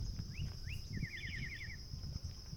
Sabiá-gongá (Saltator coerulescens)
Nome em Inglês: Bluish-grey Saltator
Localidade ou área protegida: Parque Nacional Ciervo de los Pantanos
Condição: Selvagem
Certeza: Observado, Gravado Vocal